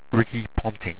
Pronounced
RICKEE PONTING